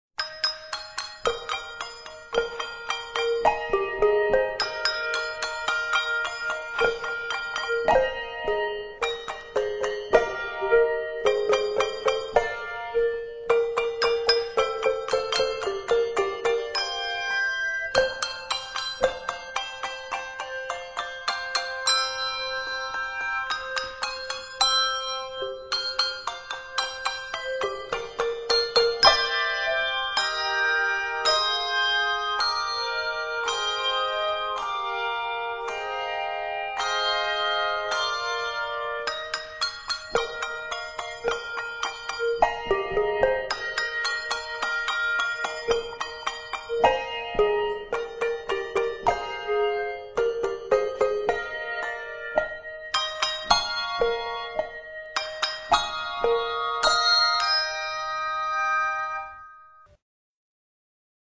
Scored in Eb Major